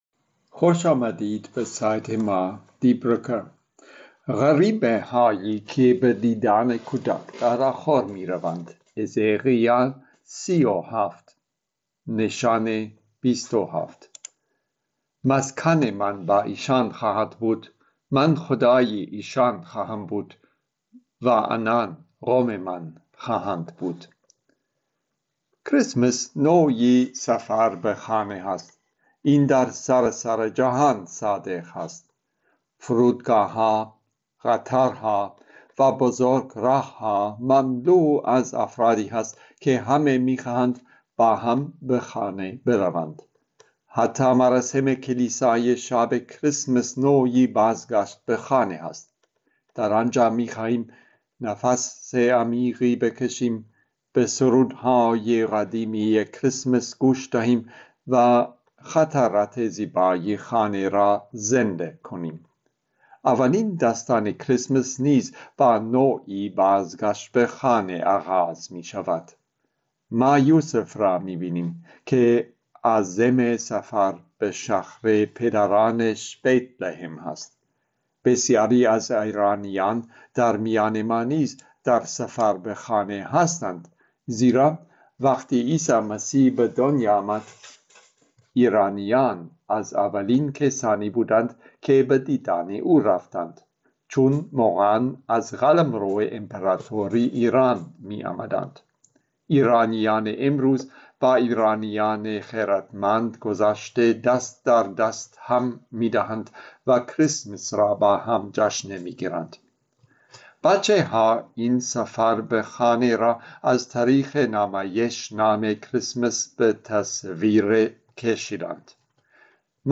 Predigten im Kirchenjahr 2025/2026